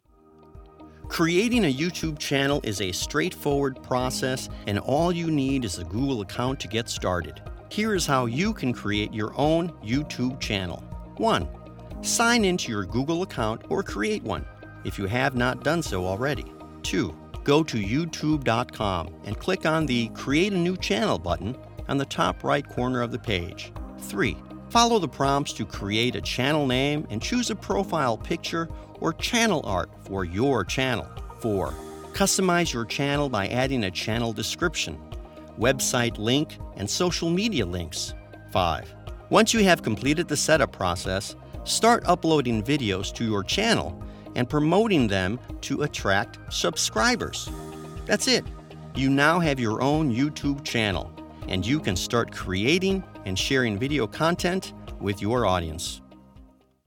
Explainer & Web Narration Demos
• A conversational, human delivery
• Broadcast-quality audio for animation or live-action
• A steady, confident tone for demos and how-tos